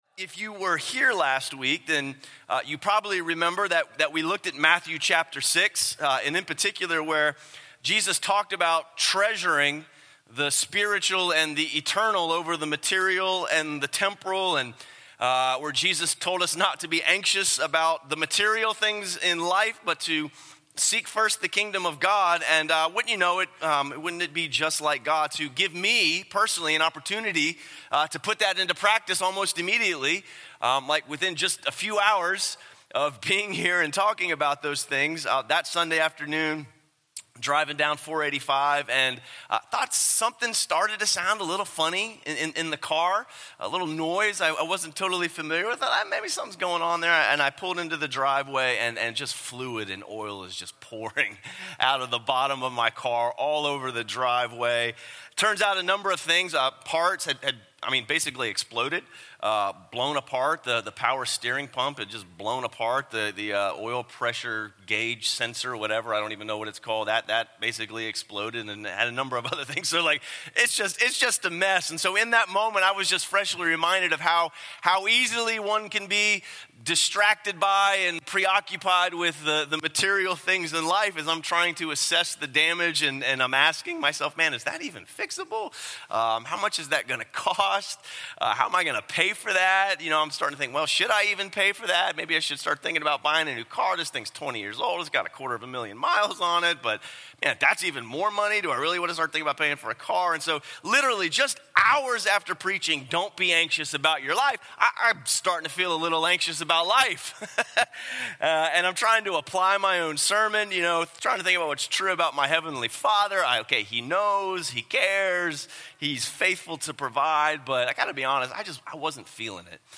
The Coming of Christ Changes Everything – Stand Alone Sermons | Crossway Community Church